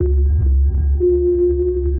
sonarPingSuitFarShuttle1.ogg